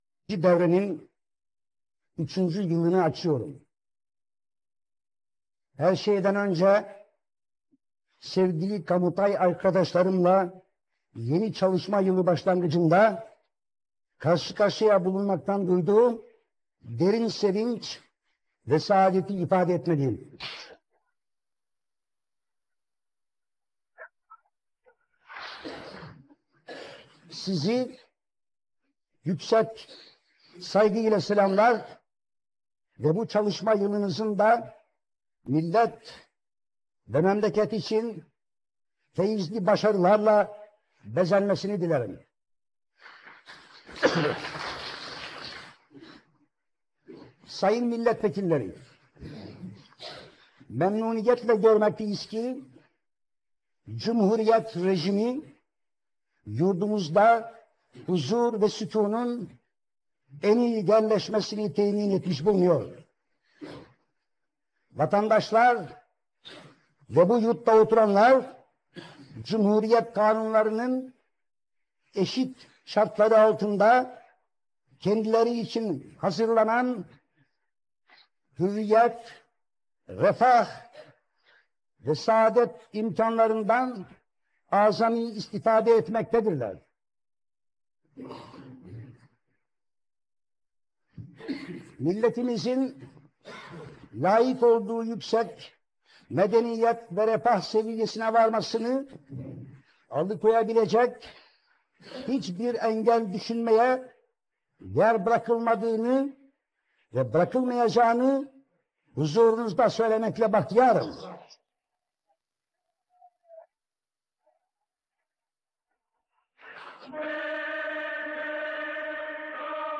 ataturk_voice_restorated